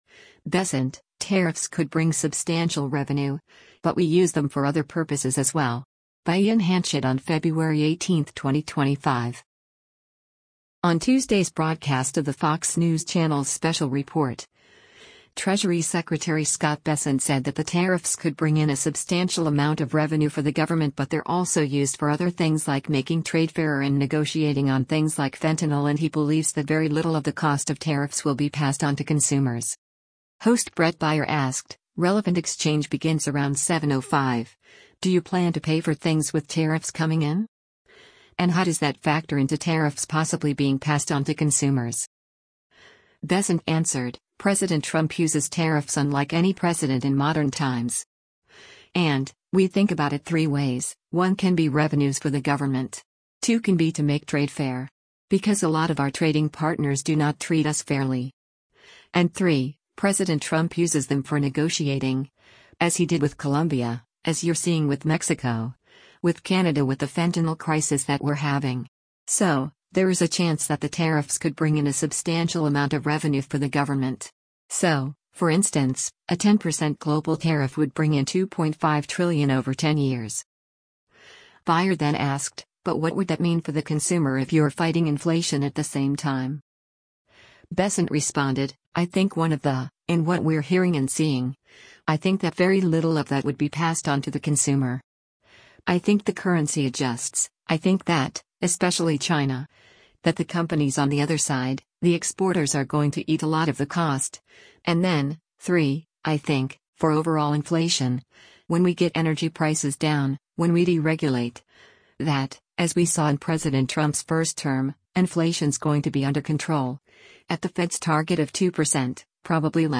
On Tuesday’s broadcast of the Fox News Channel’s “Special Report,” Treasury Secretary Scott Bessent said that “the tariffs could bring in a substantial amount of revenue for the government” but they’re also used for other things like making trade fairer and negotiating on things like fentanyl and he believes that “very little” of the cost of tariffs will be passed on to consumers.